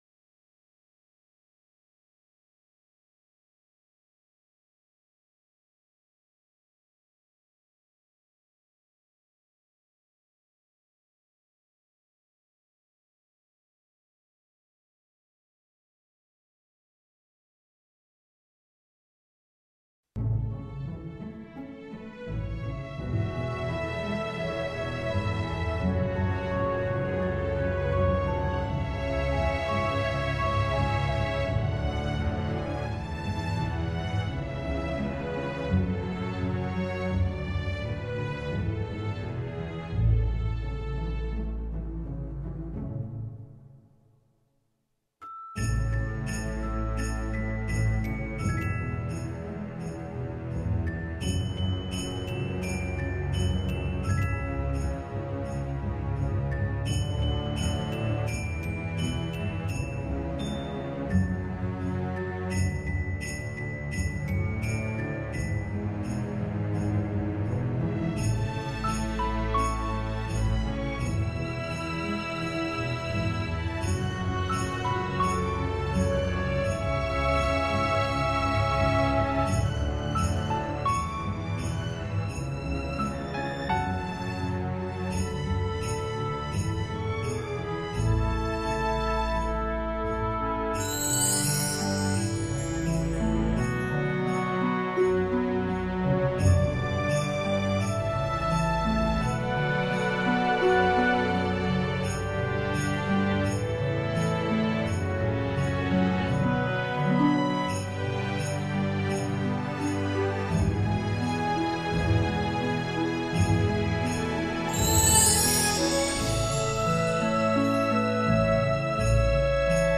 it's a popular song but using the orchestral structure ,so it feels like some kind of movie theme song
instremental version